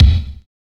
Kick (8).wav